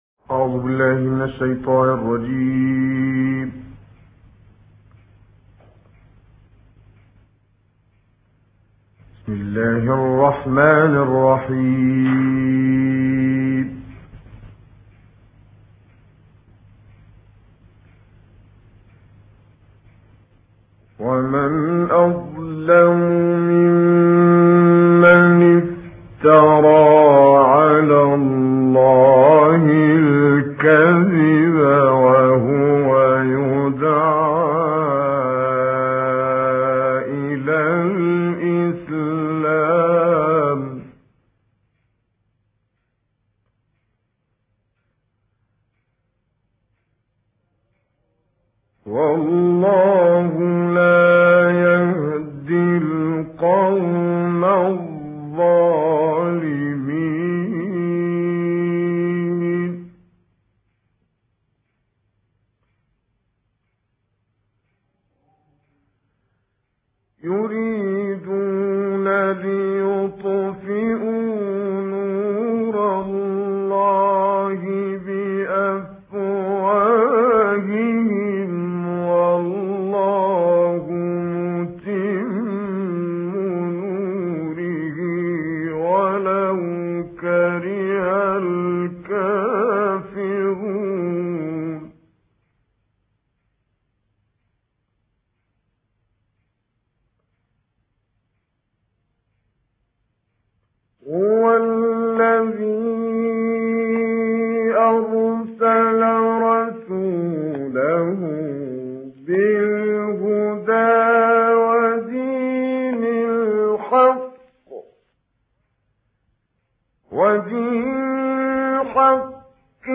Quran recitations
Reciter Kamel Yousf El Behteemy